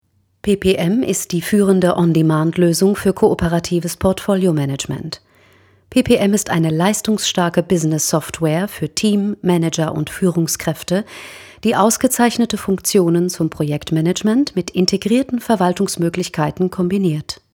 frische, dynamische Erwachsenenstimme
Sprechprobe: Industrie (Muttersprache):